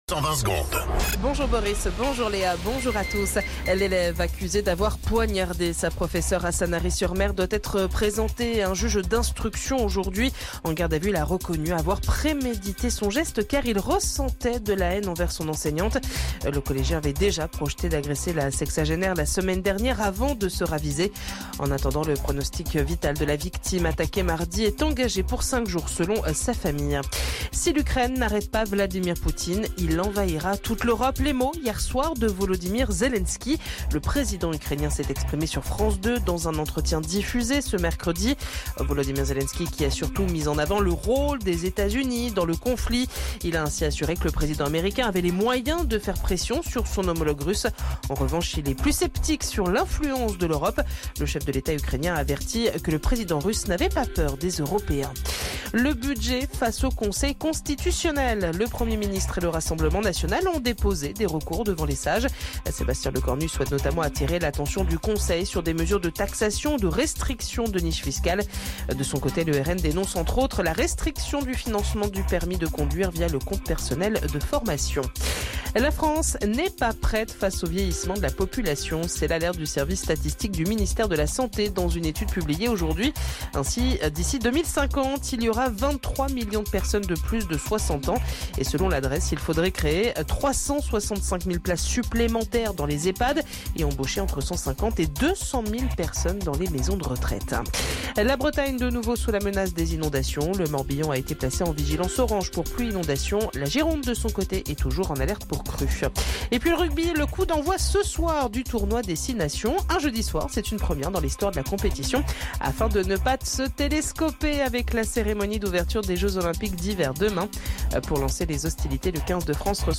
Flash Info National 05 Février 2026 Du 05/02/2026 à 07h10 .